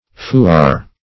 fuar - definition of fuar - synonyms, pronunciation, spelling from Free Dictionary Search Result for " fuar" : The Collaborative International Dictionary of English v.0.48: Fuar \Fu"ar\, n. Same as Feuar .